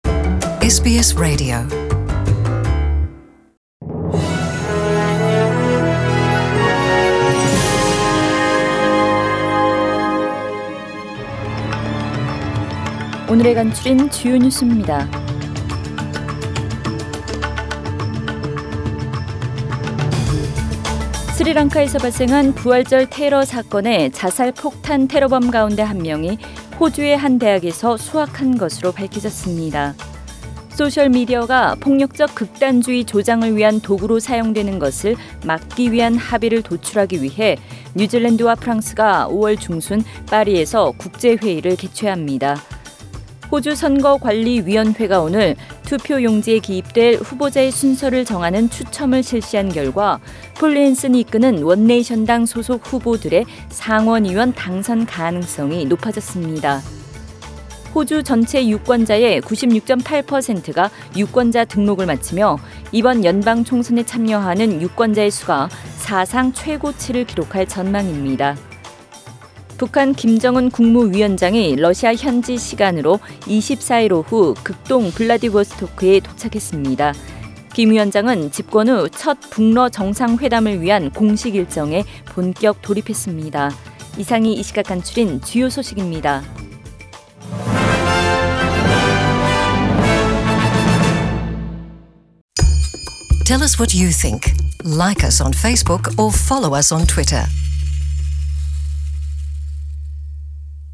SBS 한국어 뉴스 간추린 주요 소식 – 4월 24일 수요일